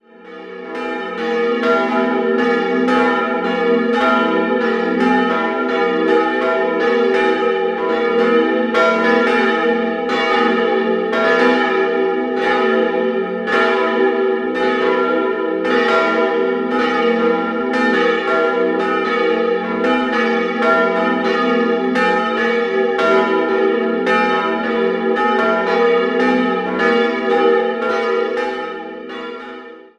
Die katholische Pfarrkirche St. Willibald wurde in den Jahren 1912/13 errichtet, zeitgleich mit der benachbarten evangelischen Kirche. Die letzte Umgestaltung des Innenraums fand Anfang der 1980er-Jahre statt. 3-stimmiges Geläut: e'-g'-gis' Die Glocken wurden im Jahr 1922 vom Bochumer Verein für Gussstahlfabrikation gegossen.